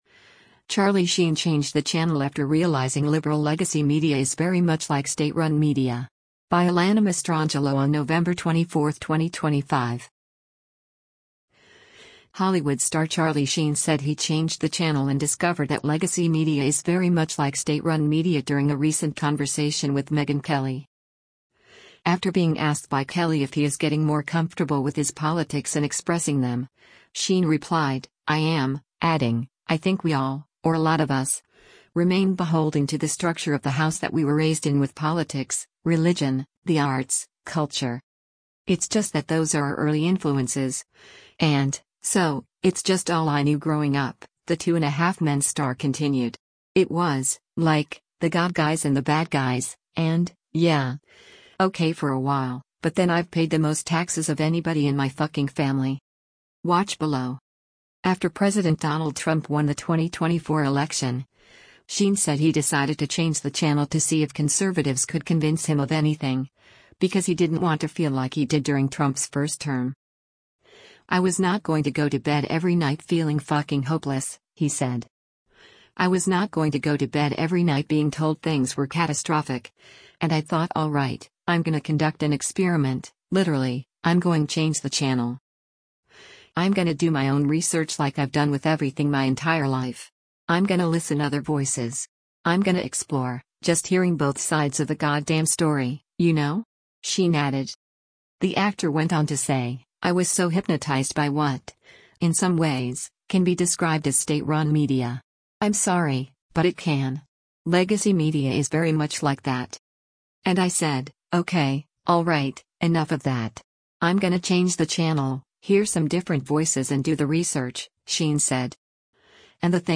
Hollywood star Charlie Sheen said he “changed the channel” and discovered that “legacy” media is “very much like state-run media” during a recent conversation with Megyn Kelly.
Kelly then chimed in, asking, “When you walked into the ballot box and voted for Trump, how did that feel?”